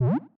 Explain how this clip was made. Also toned down the reverb on get-tool while I'm here. 2024-05-07 17:50:58 -06:00 7.2 KiB Raw History